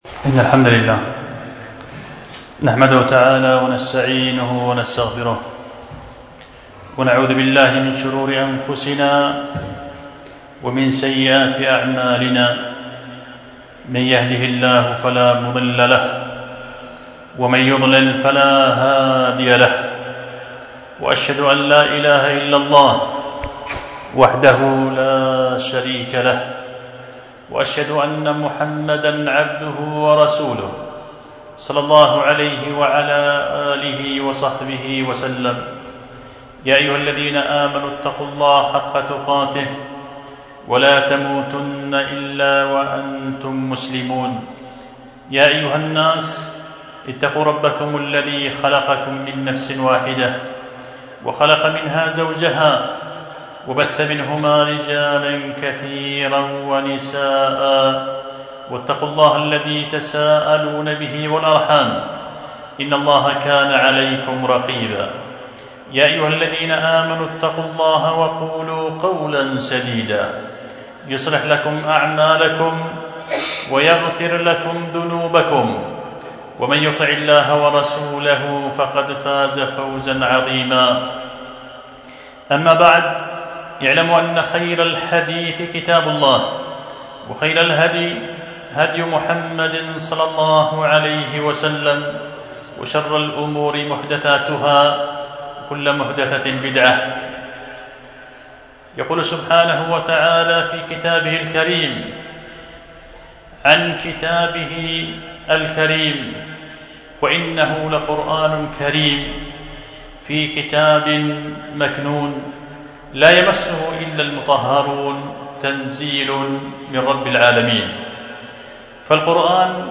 خطبة جمعة بعنوان التبيان في بيان أحوال أهل الكفران مع القرآن